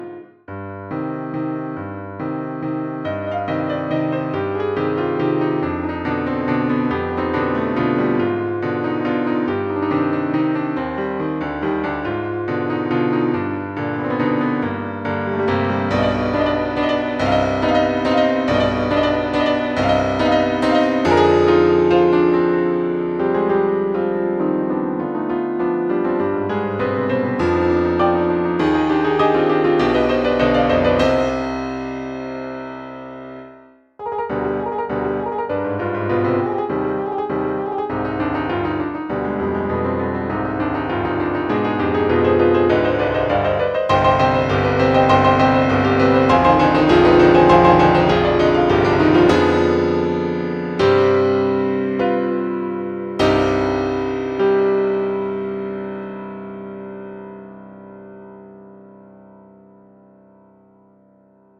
Etude no.7 - Piano Music, Solo Keyboard - Young Composers Music Forum